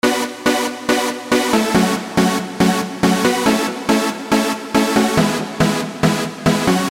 Tag: 140 bpm Dance Loops Synth Loops 1.15 MB wav Key : Unknown